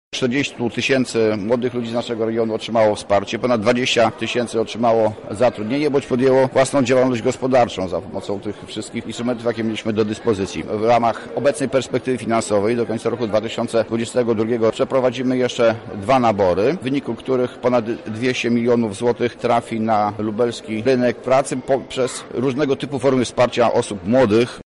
Więcej o tym, oraz o planach na przyszłość mówi dyrektor Wojewódzkiego Urzędu Pracy Andrzej Pruszkowski: